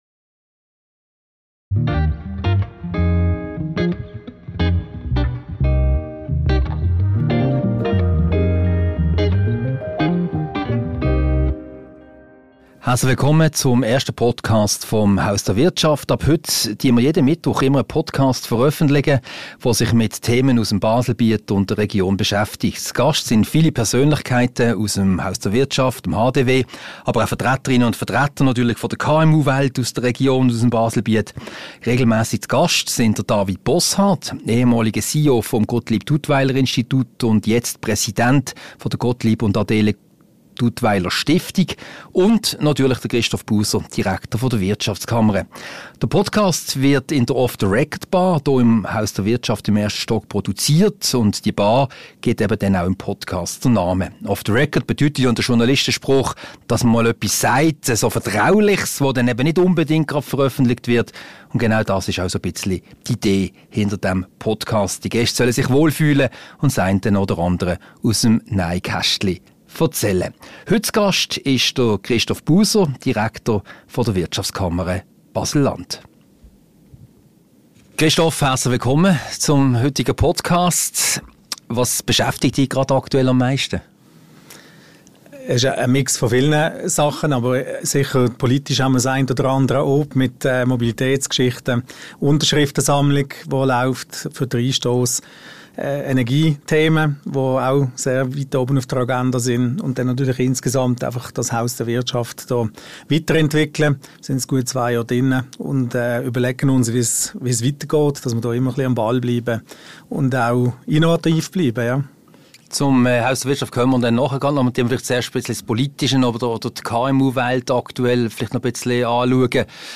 Ein Gespräch über aktuelle Themen, die Befindlichkeit der KMU-Wirtschaft in der Region, die Credit-Suisse-Übernahme und eine erste Bilanz zum Haus der Wirtschaft.